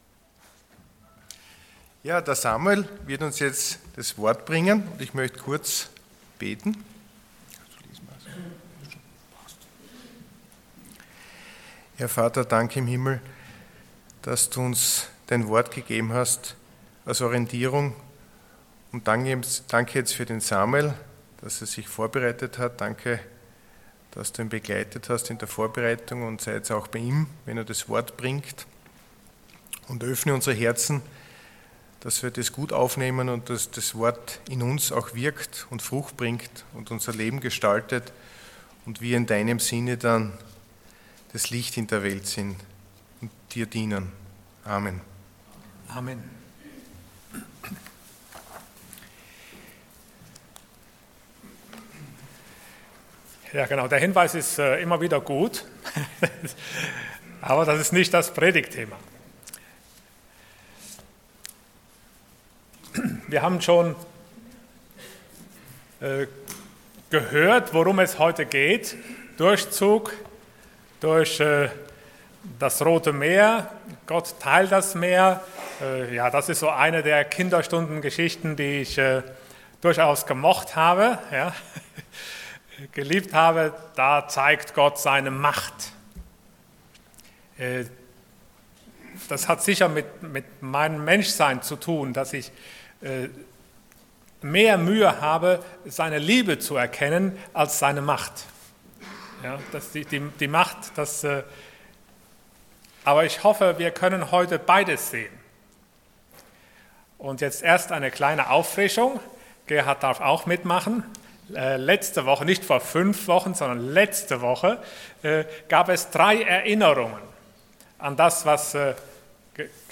Passage: 2.Mose 14,5-31 Dienstart: Sonntag Morgen